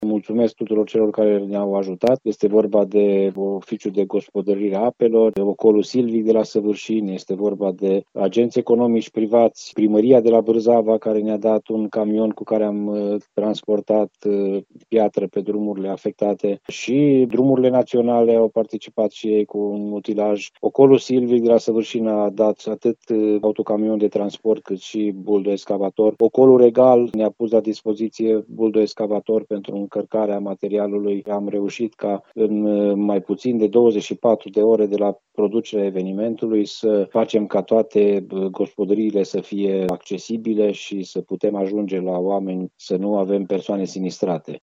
Se poate circula pe drumurile care leagă satele din componența comunei după mobilizarea mai multor instituții, spune primarul Ioan Vodicean.
1.Ioan-Vodicean-12.mp3